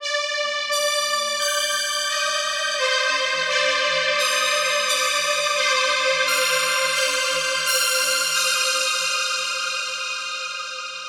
Vibes Strings 02.wav